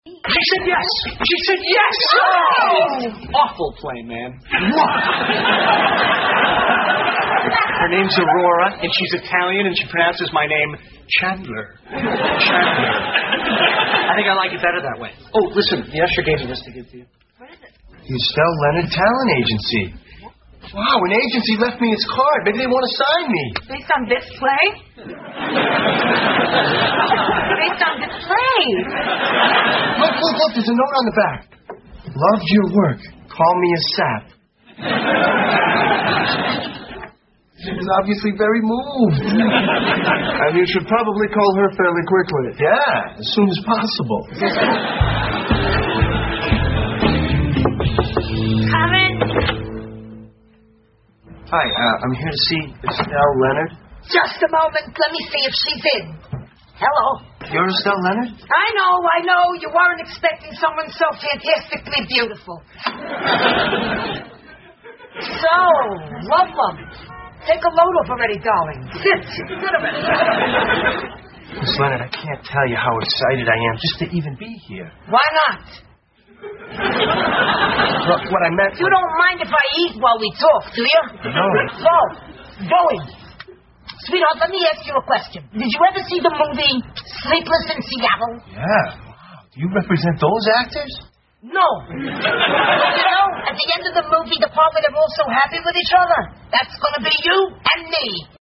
在线英语听力室老友记精校版第1季 第63期:屁股秀(3)的听力文件下载, 《老友记精校版》是美国乃至全世界最受欢迎的情景喜剧，一共拍摄了10季，以其幽默的对白和与现实生活的贴近吸引了无数的观众，精校版栏目搭配高音质音频与同步双语字幕，是练习提升英语听力水平，积累英语知识的好帮手。